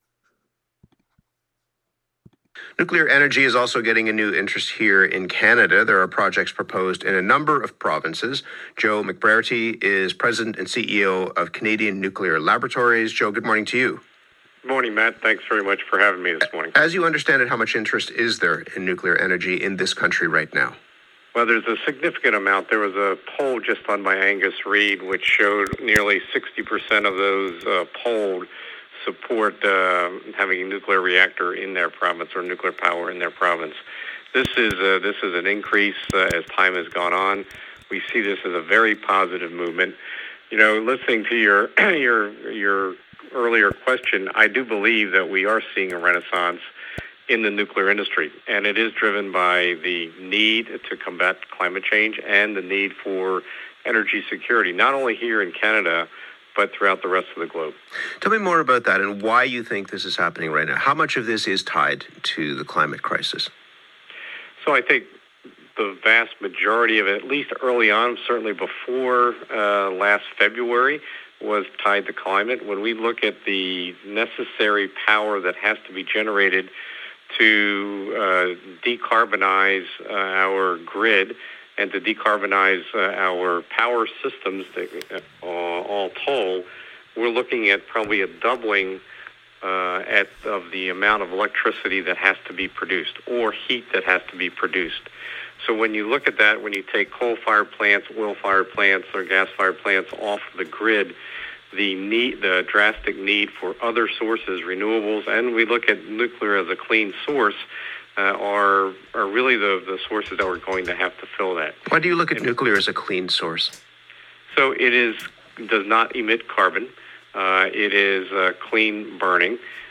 Have a listen to the interview with The Current’s Matt Galloway.